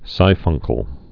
(sīfŭngkəl)